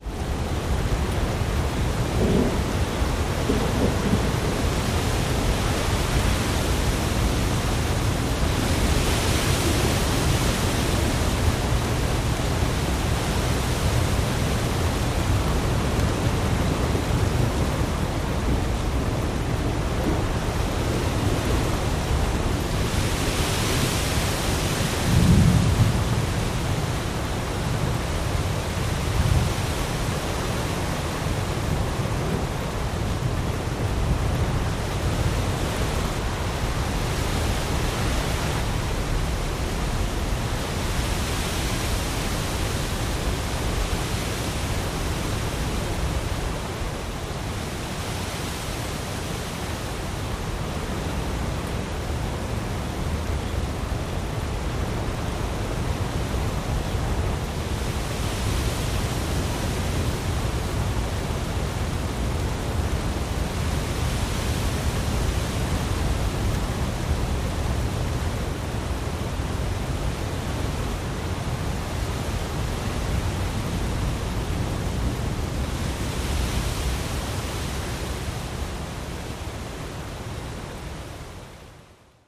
am_storm_01_hpx
Steady rain falls with light thunder.